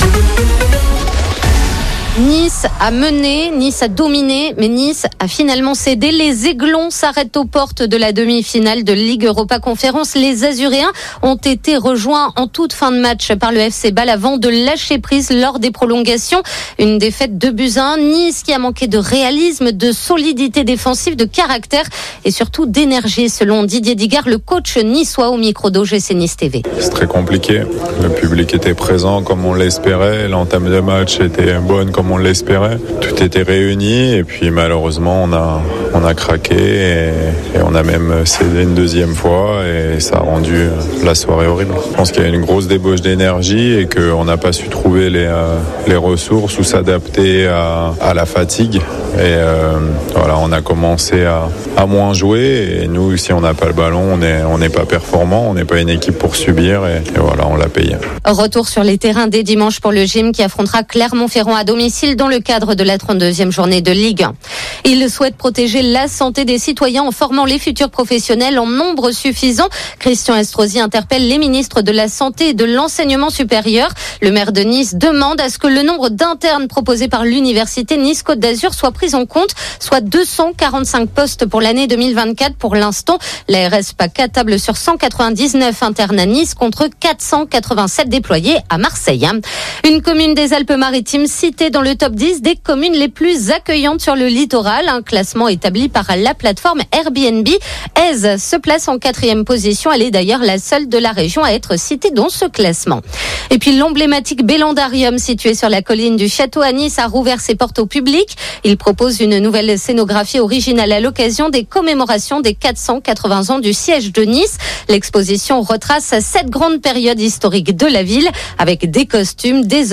Dans ce flash info du Lundi 21 avril 2023,